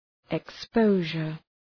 {ık’spəʋʒər}